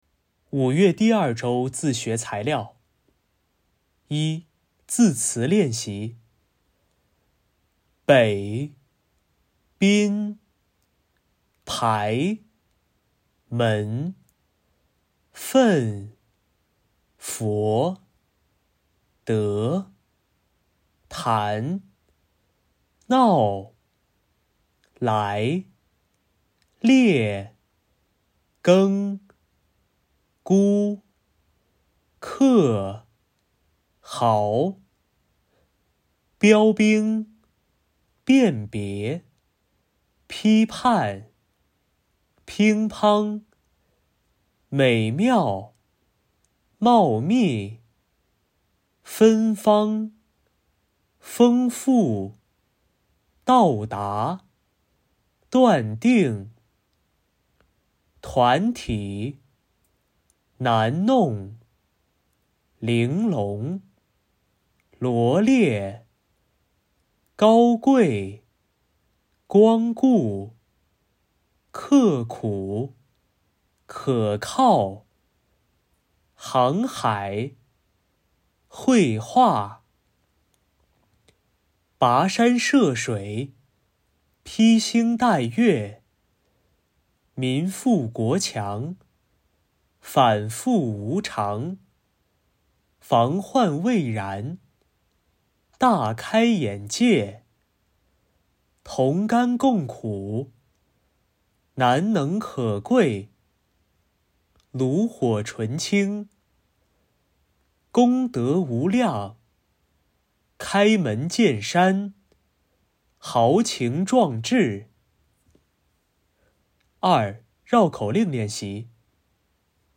领读课件